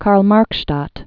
(kärl-märkshtät)